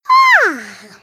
yawn
Category: Games   Right: Personal